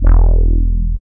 72.04 BASS.wav